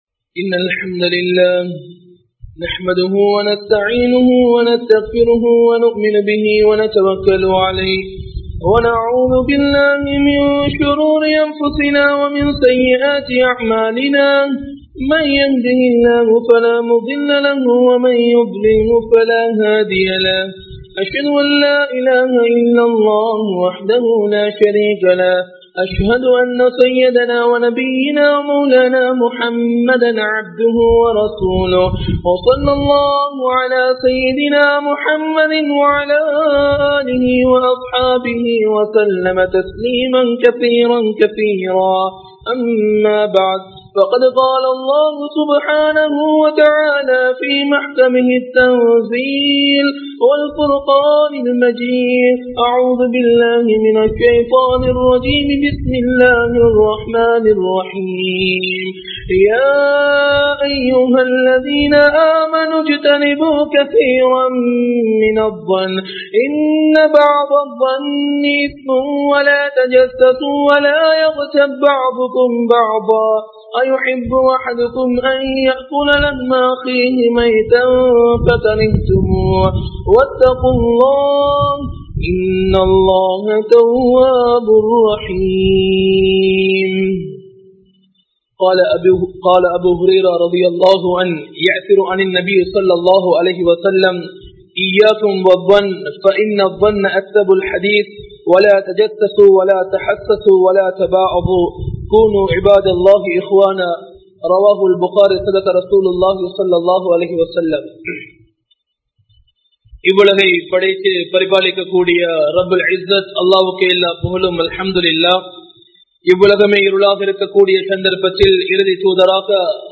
Thavaraana Ennaggal (தவறான எண்ணங்கள்) | Audio Bayans | All Ceylon Muslim Youth Community | Addalaichenai